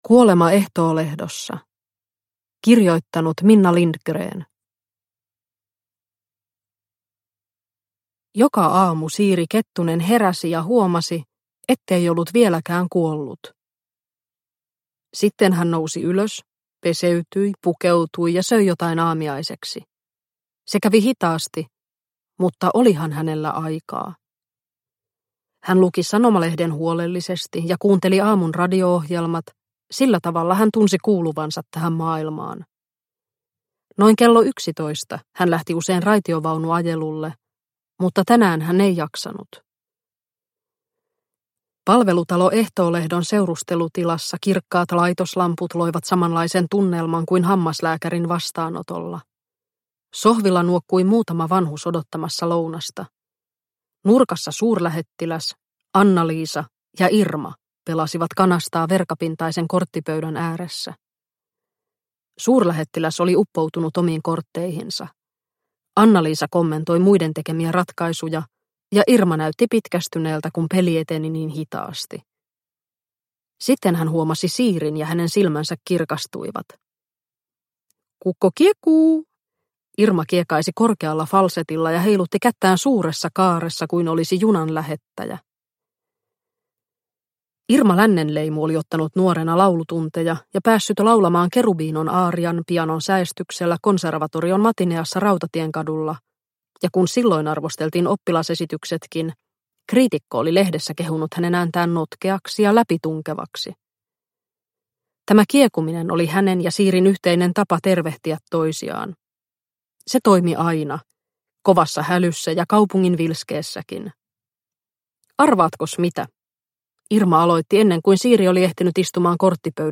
Kuolema Ehtoolehdossa – Ljudbok – Laddas ner